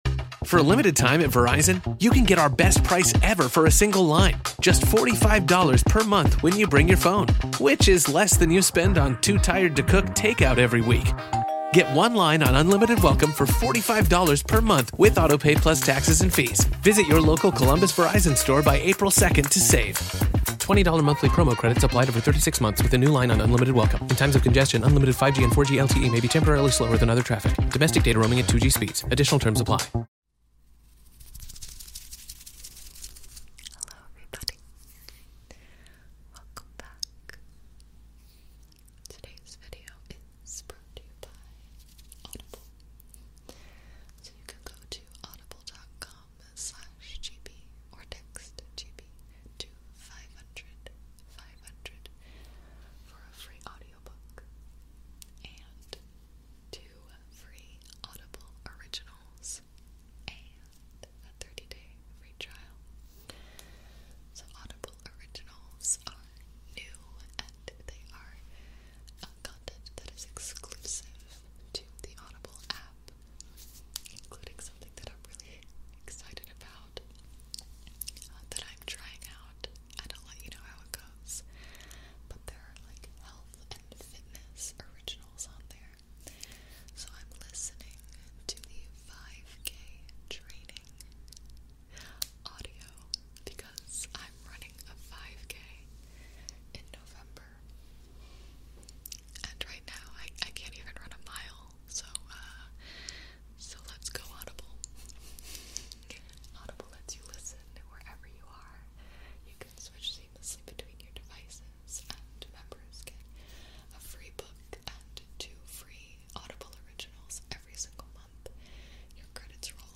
ASMR skincare chat & tingly clay mask application. ASMR switching off anxious thoughts.